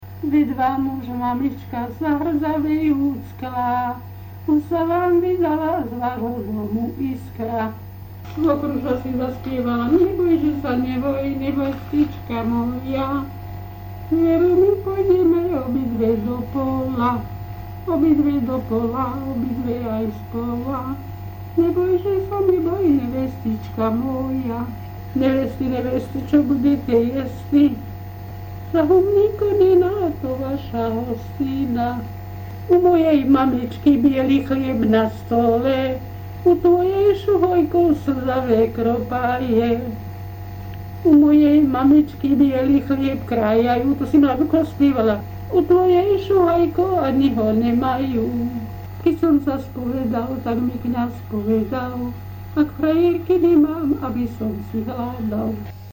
Descripton sólo ženský spev bez hudobného sprievodu
General description Melódiu piesne radíme medzi tzv. svadobné nôty. Spievala sa v rôznych fázach svadby.
Okrem iných špecifík sa vyznačovalo tvrdou výslovnosťou hláskových skupín -de, -te, -ne, -le, -di, -ti, -ni, -li.
Place of capture Likavka